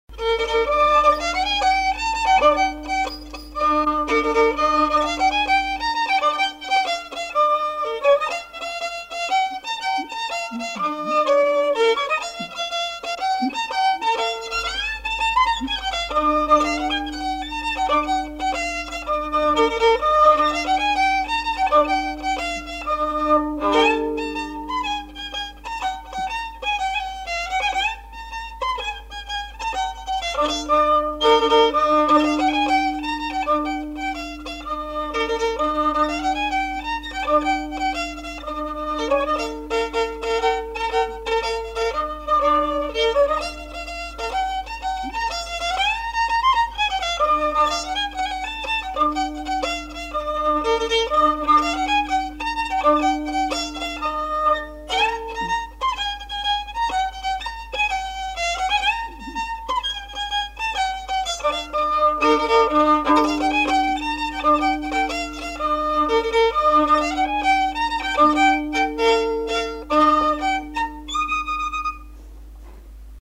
Aire culturelle : Lomagne
Lieu : Garganvillar
Genre : morceau instrumental
Descripteurs : rondeau
Instrument de musique : violon